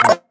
rank-down.wav